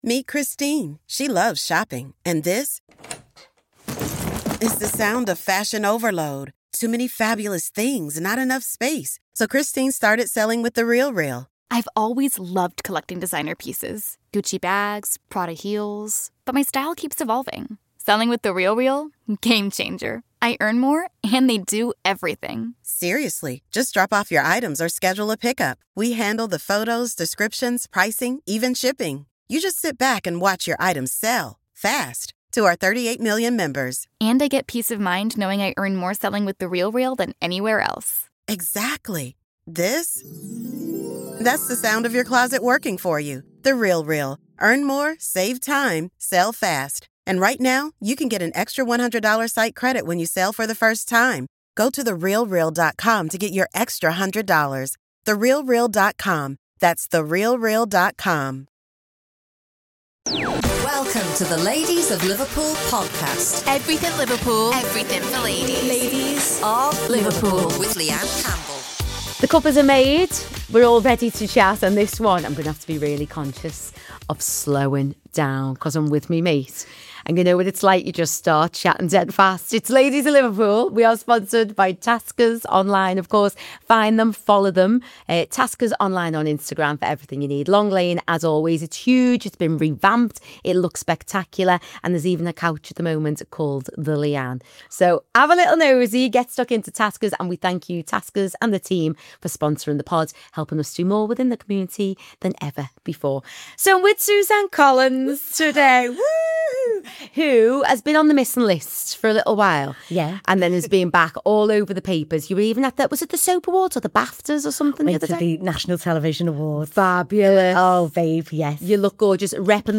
It’s an honest, warm, and nostalgic conversation that celebrates not only her career but also the resilience and humour that’s kept her shining through it all. Tune in for a mix of laughter, memories, and pure Scouse charm.